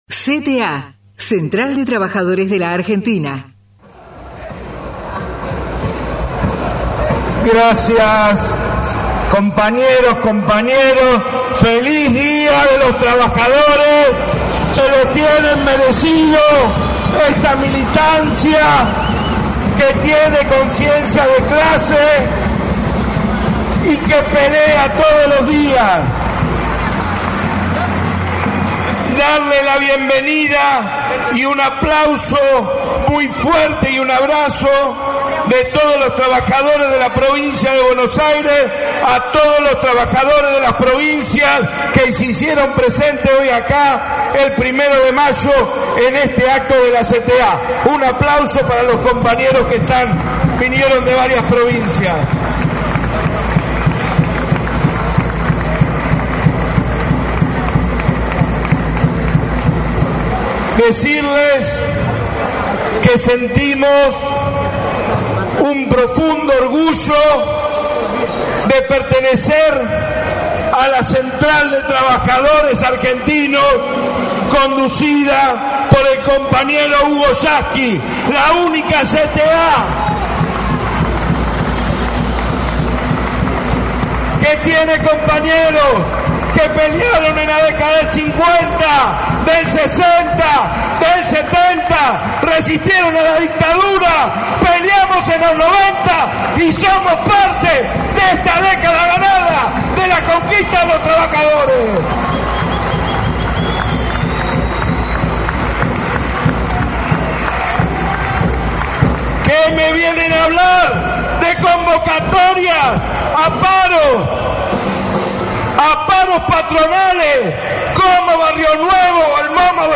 ROBERTO BARADEL - ACTO 1º DE MAYO - RACING CLUB, AVELLANEDA